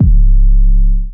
Lean808.wav